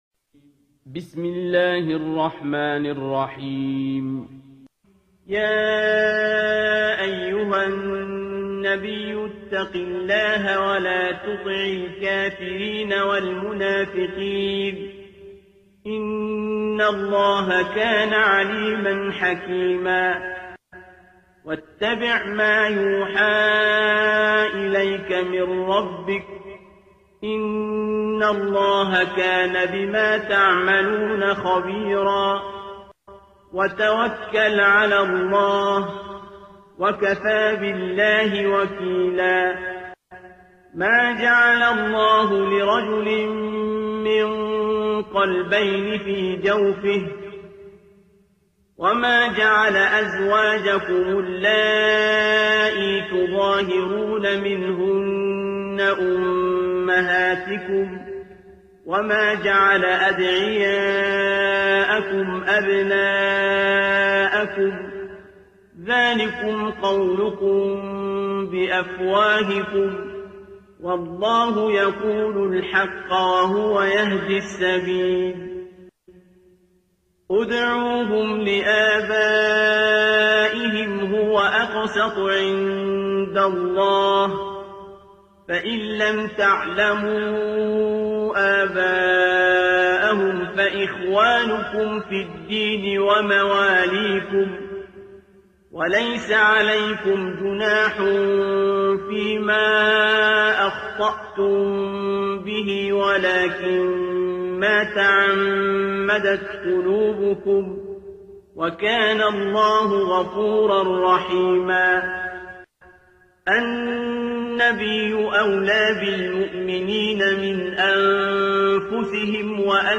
ترتیل سوره احزاب با صدای عبدالباسط عبدالصمد